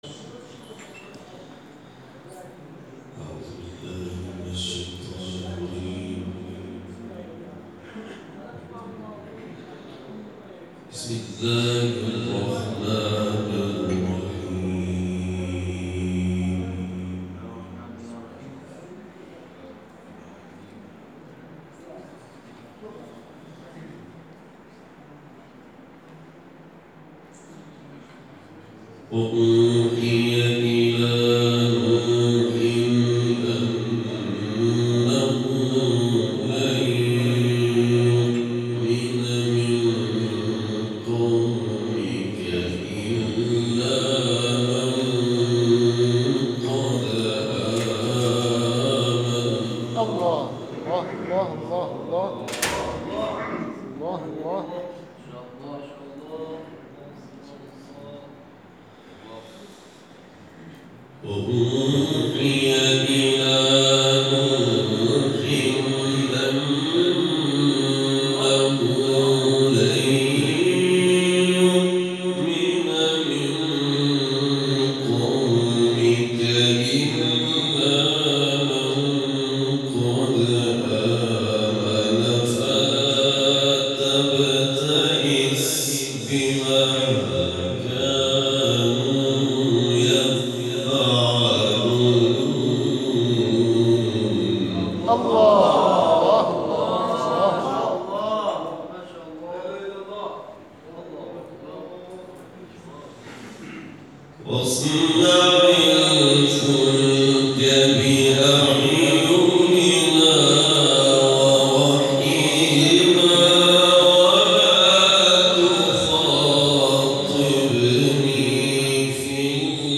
تلاوت آیاتی از سوره هود